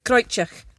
[krOYt-yeeachk]